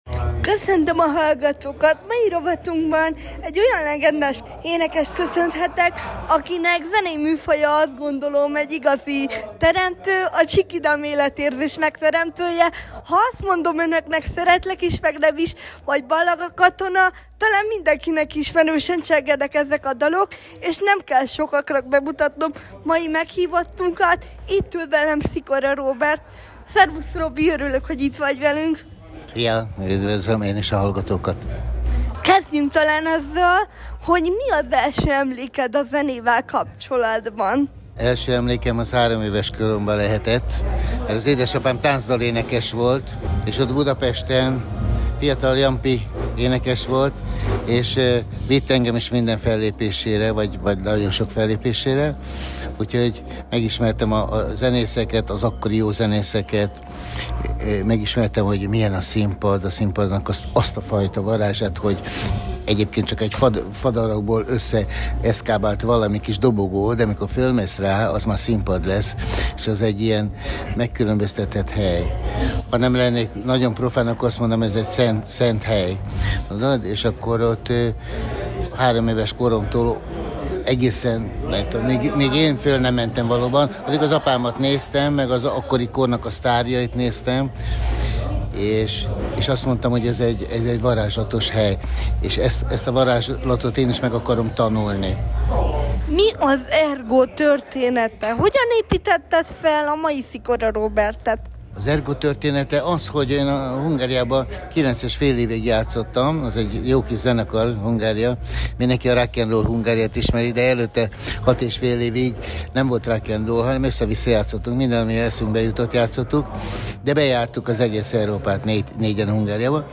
Idei év Augusztusában a Vásárhelyi Forgatag keretein belül fellépett az R-GO, nekem is így sikerült Robival egy rövid interjút készíteni és olyan fontos témákról beszélgettünk, amelyek azt gondolom sokszor mindenki számára máig élő gondolatok. Beszélgettünk az R-GO kezdeteiről, megélésekről, világlátásról, Istenbe vetett hit mélységeiről, mesterekről és arról is, hogy milyen egy jelenleg ismert bandával együtt dolgozni.